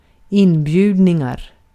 Ääntäminen
IPA : /ˈɪn.vaɪt/